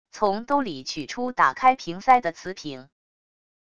从兜里取出打开瓶塞的瓷瓶wav音频